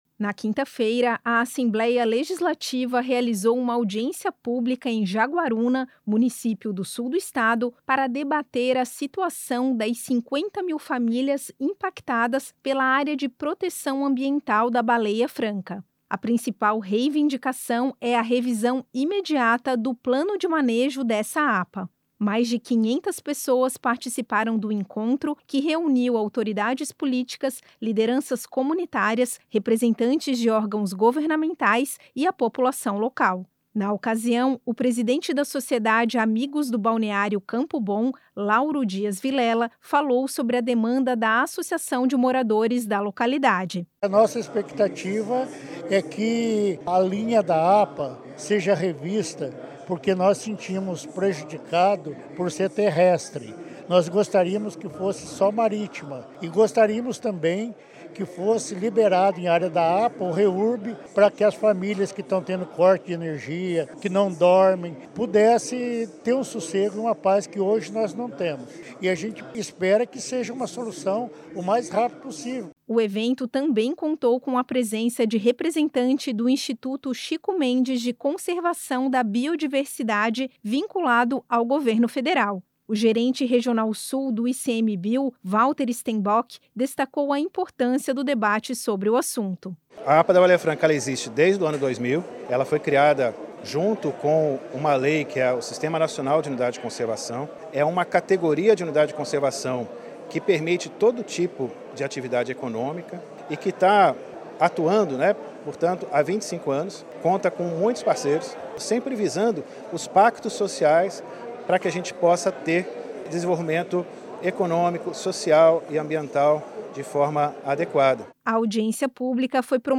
Entrevistas com:
- deputado Volnei Weber (MDB), presidente da Comissão Mista da Alesc que debate o Plano de Manejo da APA da Baleia Franca;
- deputado José Milton Scheffer (PP), relator da Comissão Mista da Alesc que debate o Plano de Manejo da APA da Baleia Franca;